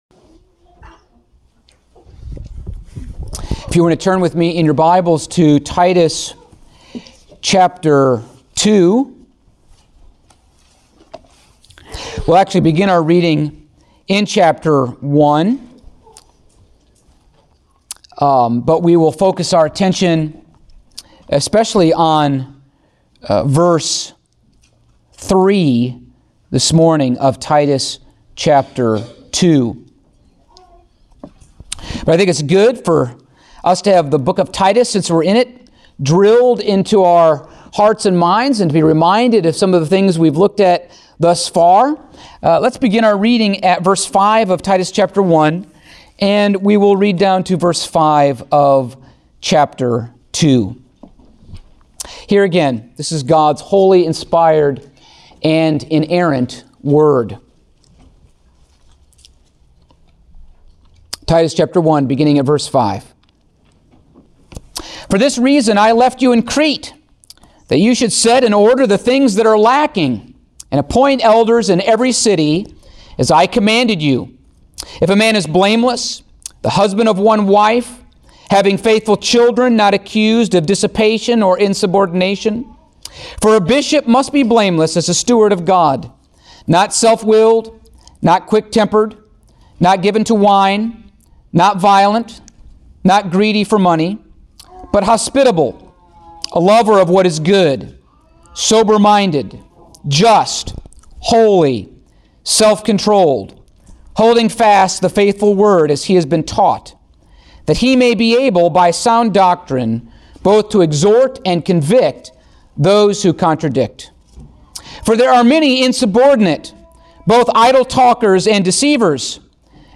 Passage: Titus 2:3 Service Type: Sunday Morning